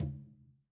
Tumba-HitN_v3_rr1_Sum.wav